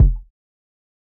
Kick (4).wav